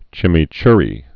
(chĭmē-chrē)